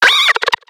Cri de Flamoutan dans Pokémon X et Y.